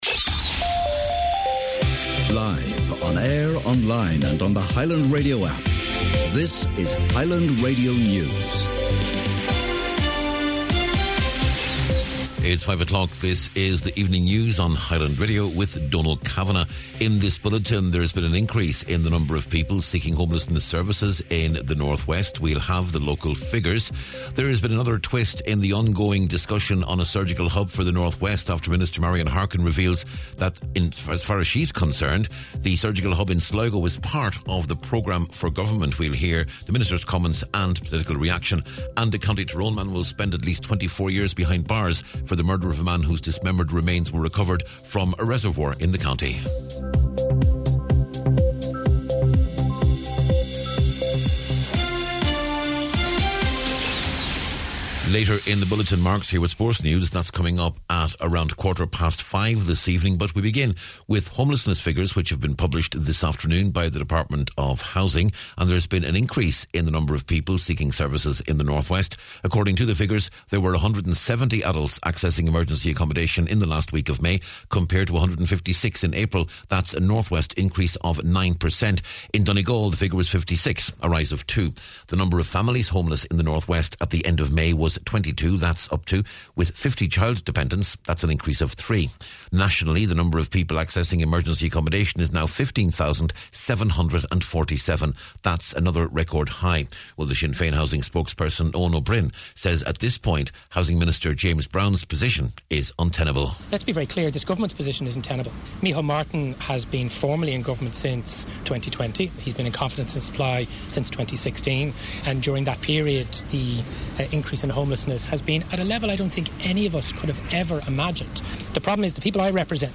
News, Sport, Nuacht and Obituaries on Friday June 27th